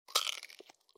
دانلود آهنگ دعوا 11 از افکت صوتی انسان و موجودات زنده
جلوه های صوتی
دانلود صدای دعوای 11 از ساعد نیوز با لینک مستقیم و کیفیت بالا